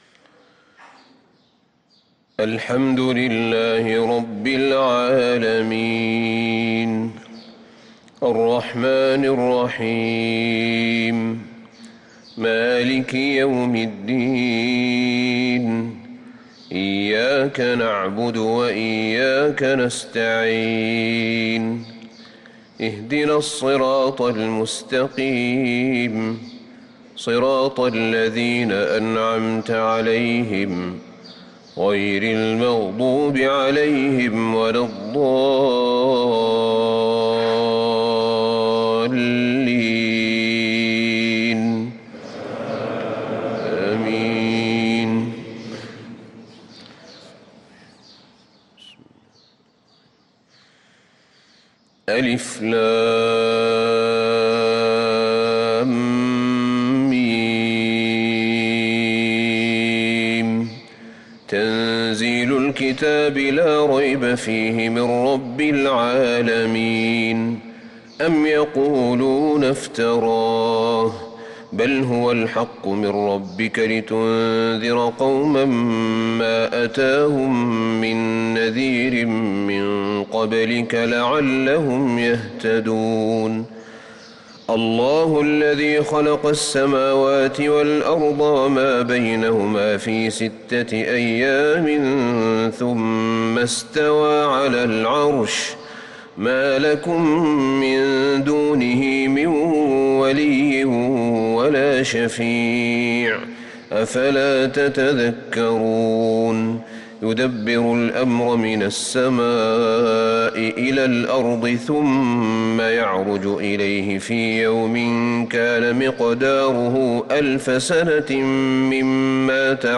صلاة الفجر للقارئ أحمد بن طالب حميد 6 شعبان 1445 هـ
تِلَاوَات الْحَرَمَيْن .